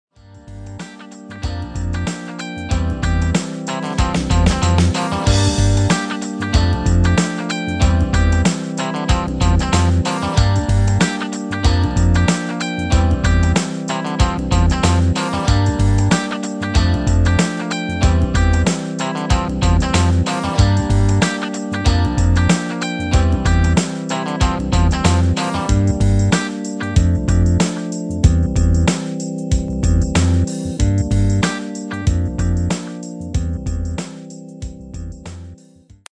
Demo/Koop midifile
Genre: R&B / Soul / Funk
- Vocal harmony tracks
Demo's zijn eigen opnames van onze digitale arrangementen.